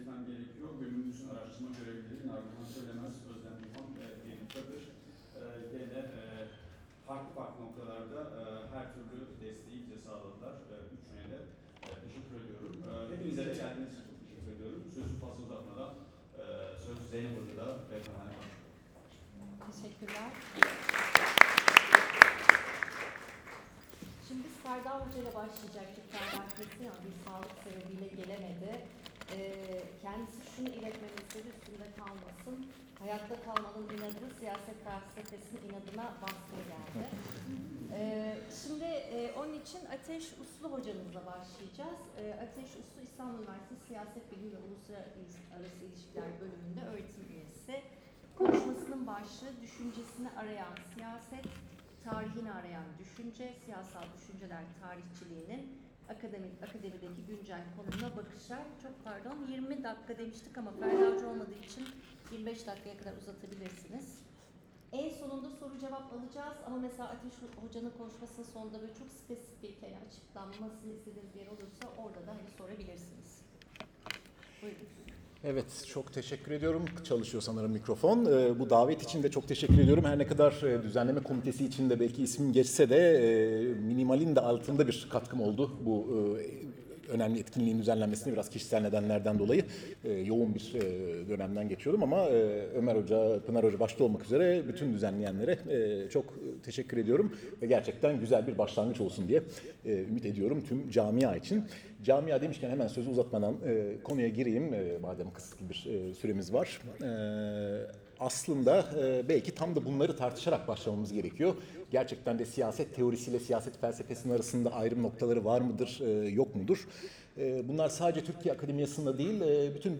Mekan: İstanbul Bilgi Üniversitesi
Bilgi-konferansi.m4a